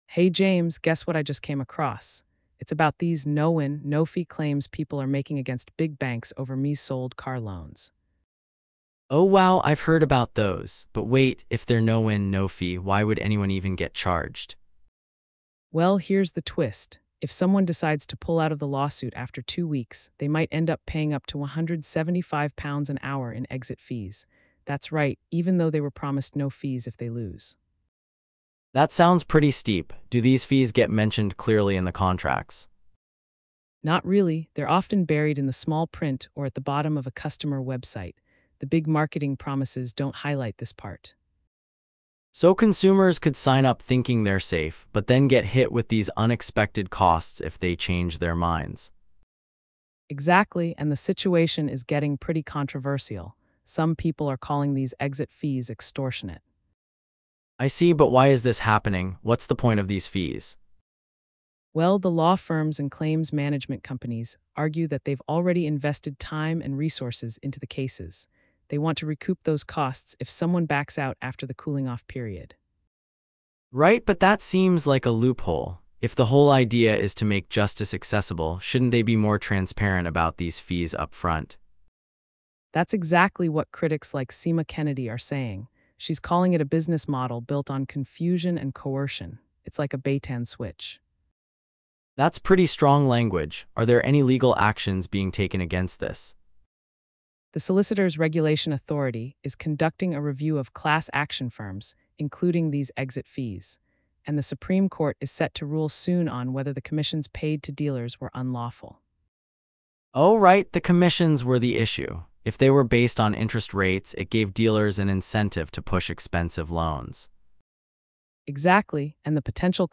They reveal how exit fees, often buried in small print, can hit claimants with unexpected costs if they pull out of a case. The duo discusses the controversy surrounding these fees, including accusations of 'bait-and-switch' tactics and the legal review of class action firms.